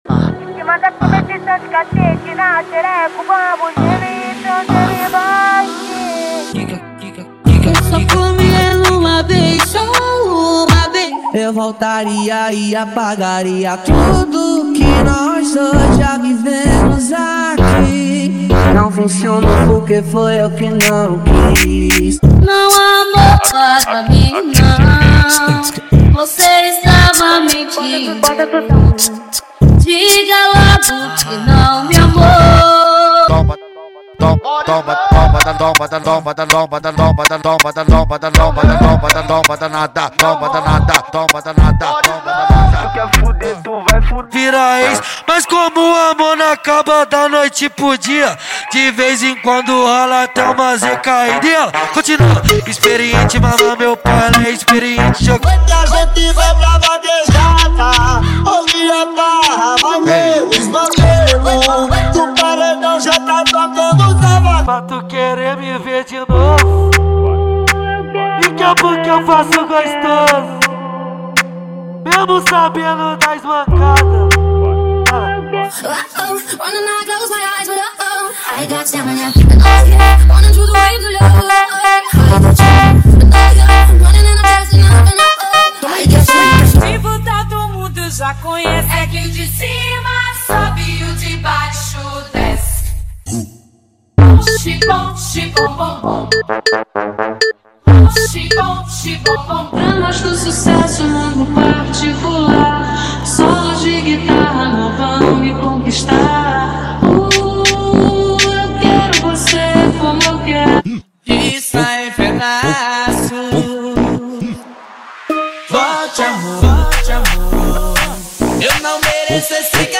• Em Alta Qualidade